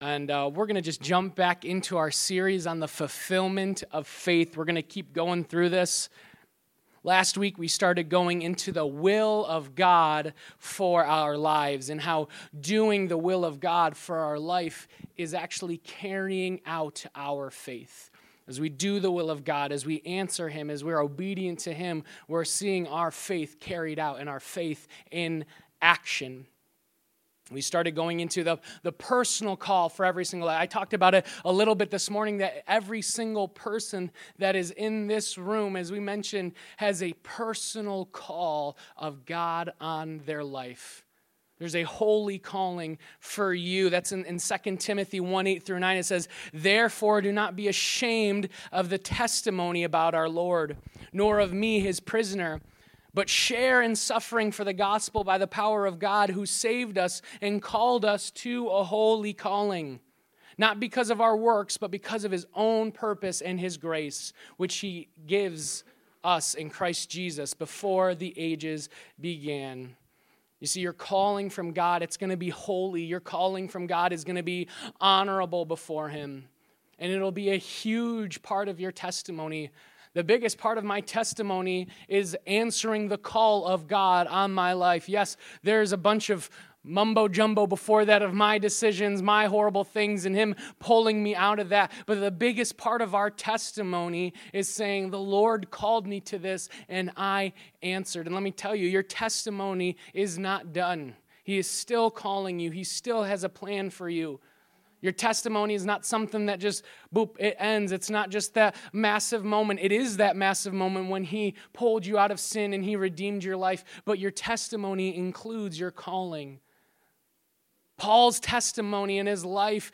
2.2.25-Sermon-Audio.mp3